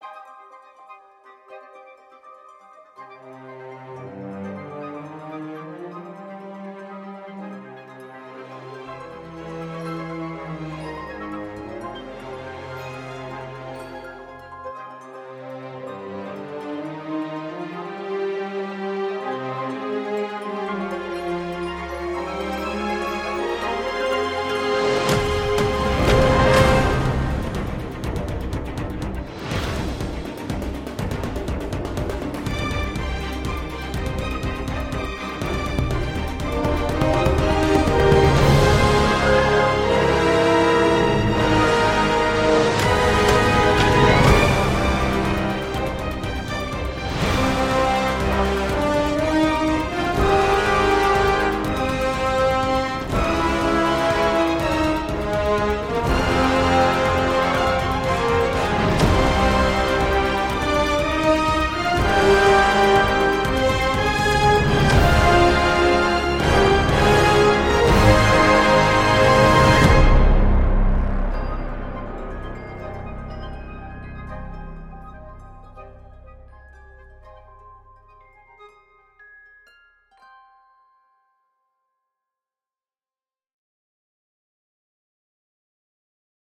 livre une musique alliant sobriété et puissance.